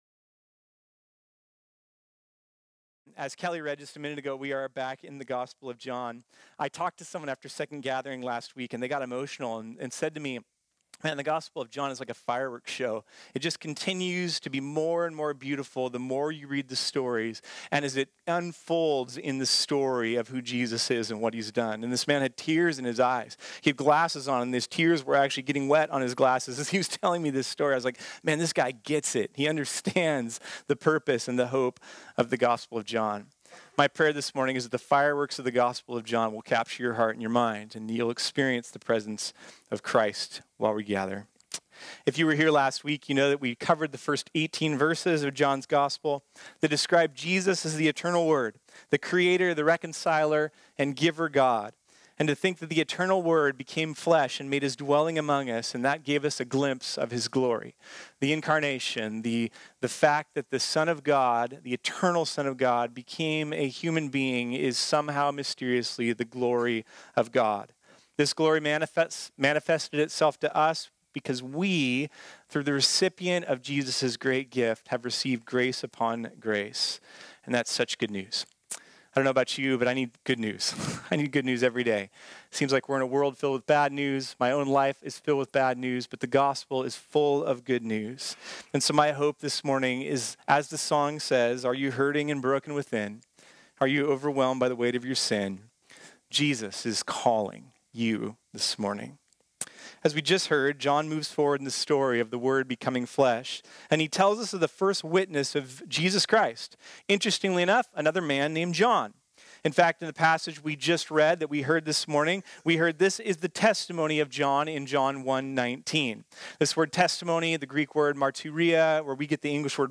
This sermon was originally preached on Sunday, September 15, 2019.